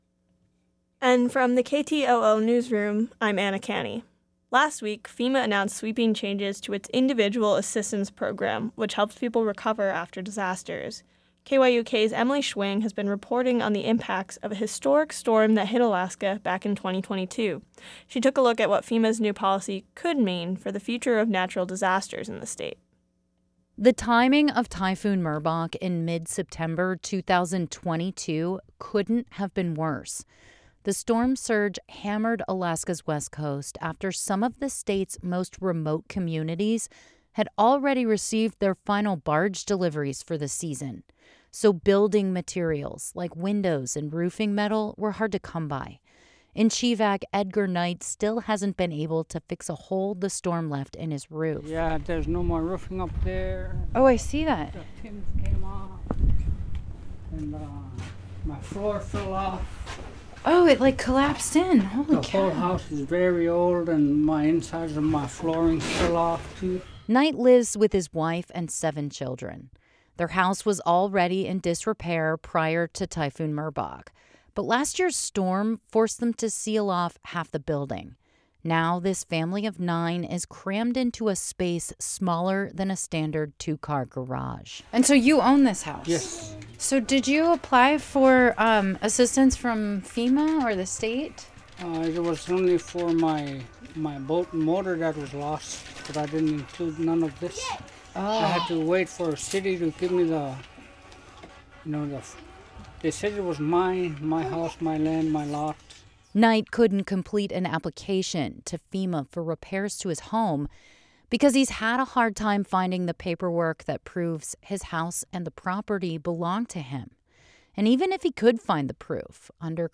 Newscast – Tuesday, Jan. 23, 2024